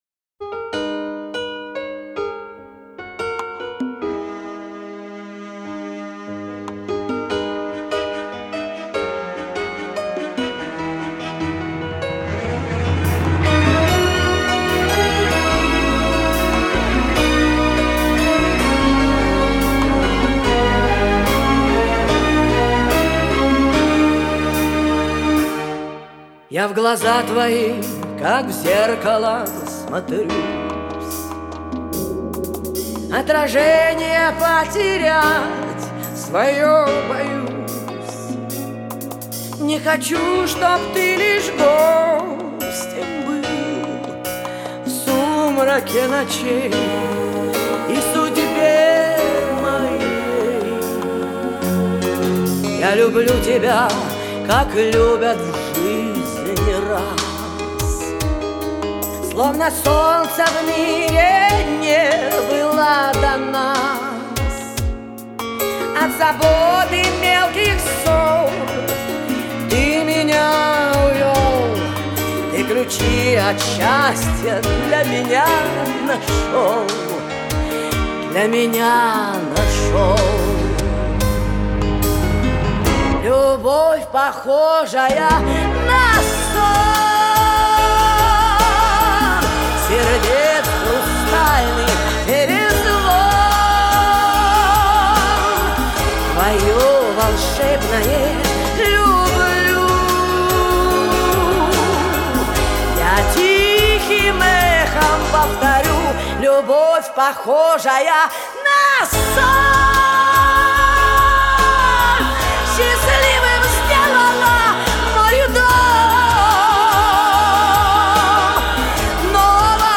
Авторы - у рояля!!